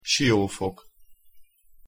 IPA key (phonetics) for the right pronunciation of the city name Siófok is: [ˈʃiʲoːfok]
pronunciation_hu_siófok.mp3